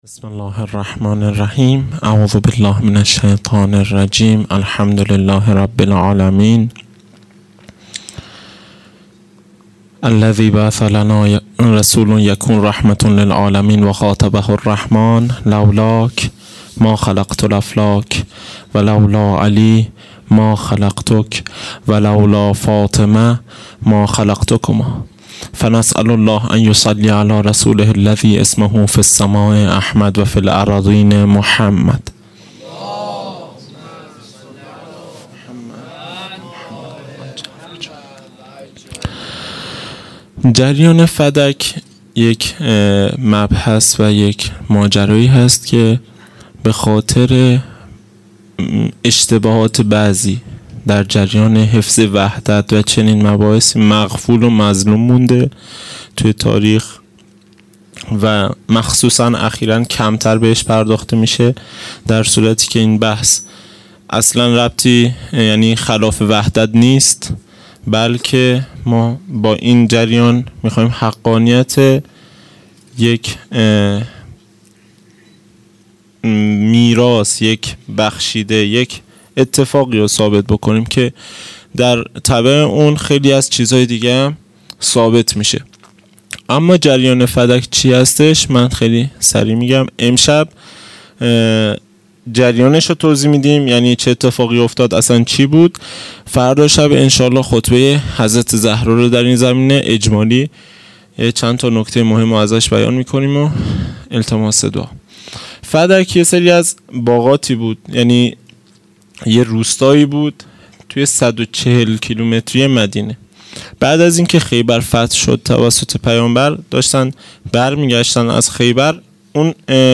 عزاداری فاطمیه اول | شب دوم 29 دی 1397